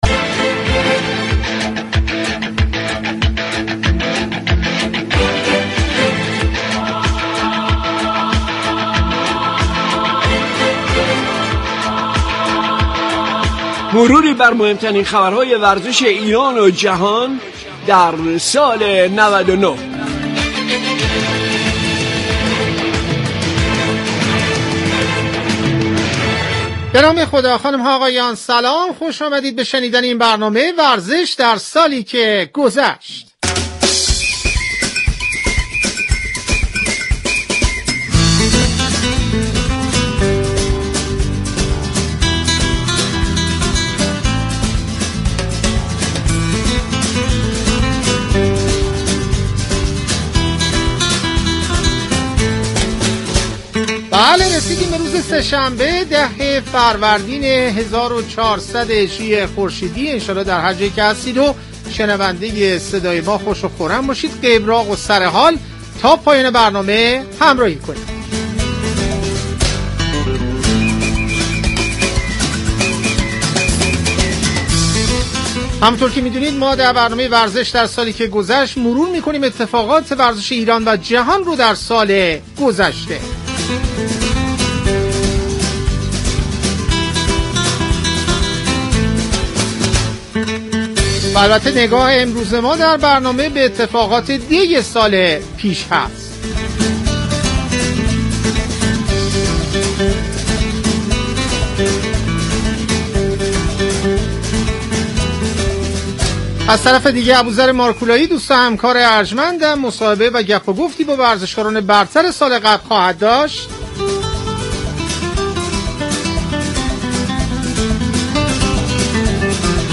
شما می توانید از طریق فایل صوتی پیوست شنونده این مصاحبه باشید. "ورزش در سالی كه گذشت" به بررسی رخدادهای مهم ورزش سال 1399 و مصاحبه با ورزشكاران می پردازد.